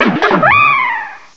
cry_not_darumaka.aif